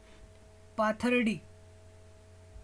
pronunciation is a town and a municipal council in Ahmednagar district in the Indian state of Maharashtra.